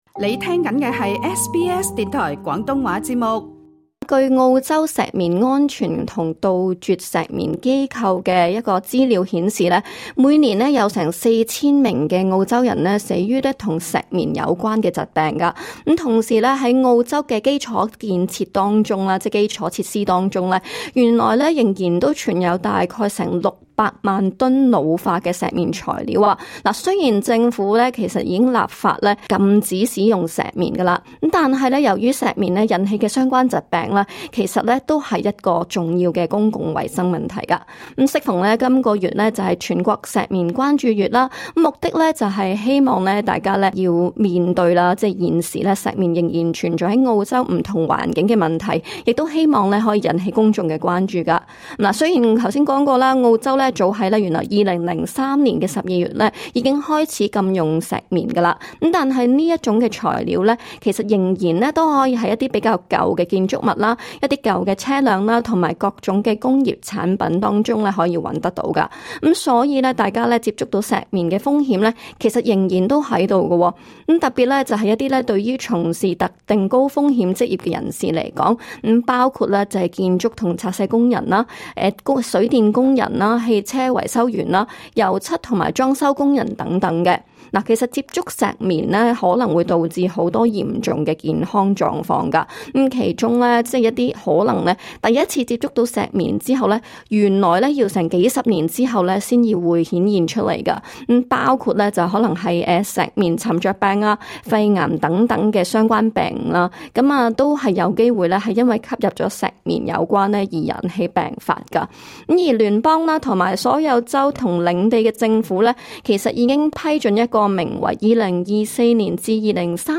今集【大眾論壇】跟聽眾們討論一下，「你擔心自己會否曾經暴露於石棉之中？